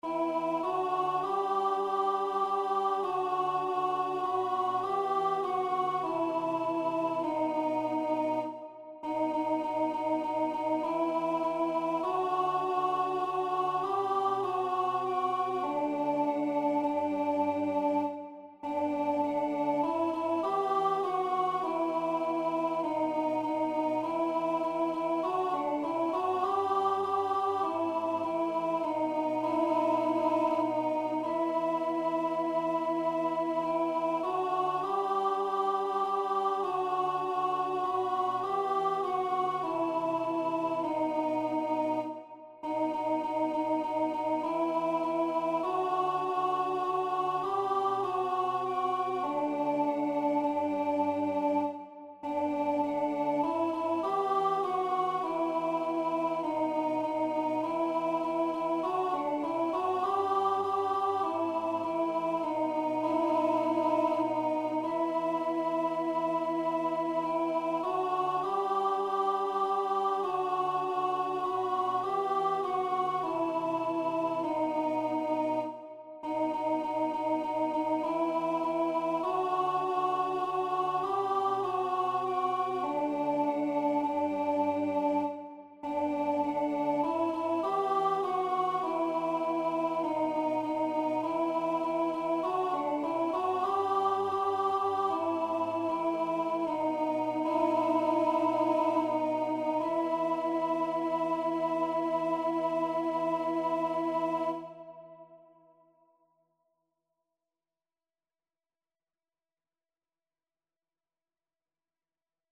2ª Voz